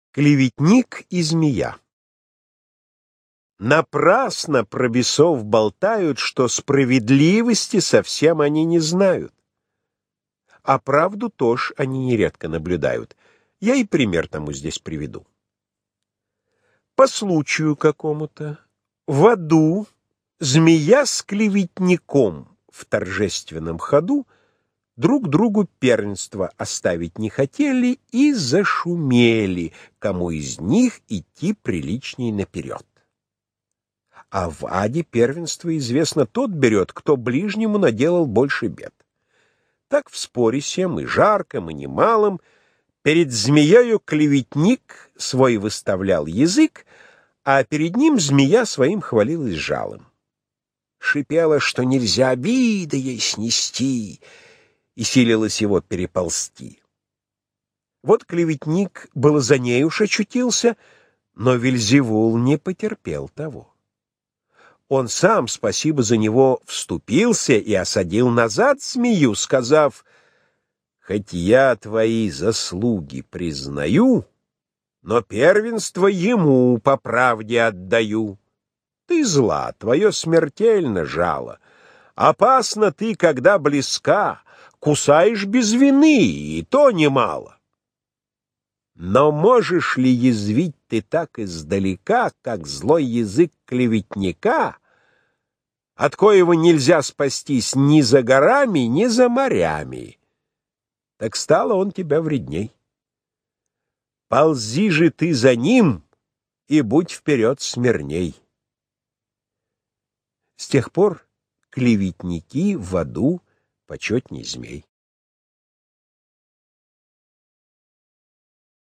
1. «Иван Крылов – Клеветник и змея (исполняет Владимир Самойлов)» /
Krylov-Klevetnik-i-zmeya-ispolnyaet-Vladimir-Samoylov-stih-club-ru.mp3